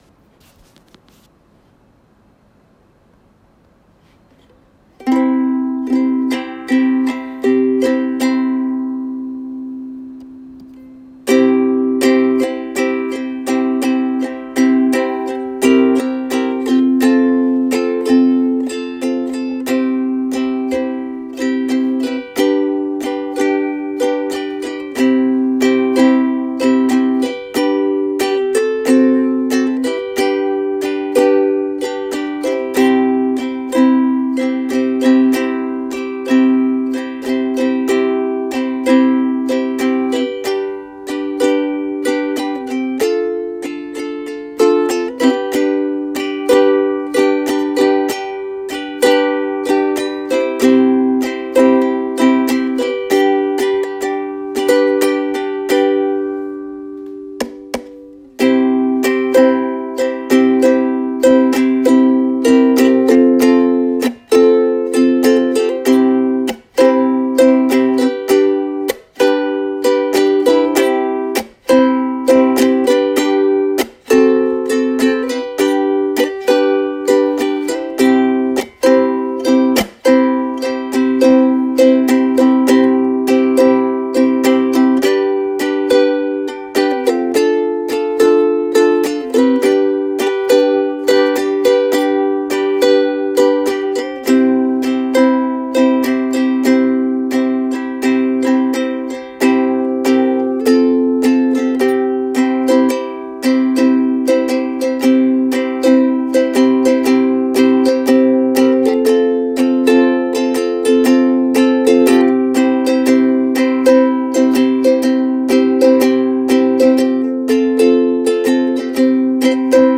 この週末は、画を描いたり、ウクレレ弾いたりして過ごしましたよ。